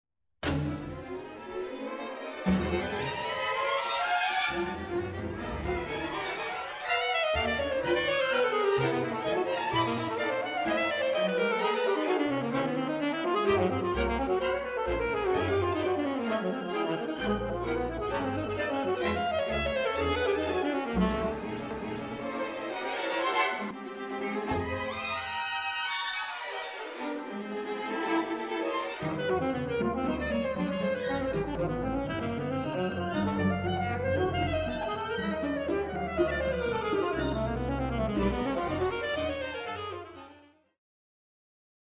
Concerto for Alto Saxophone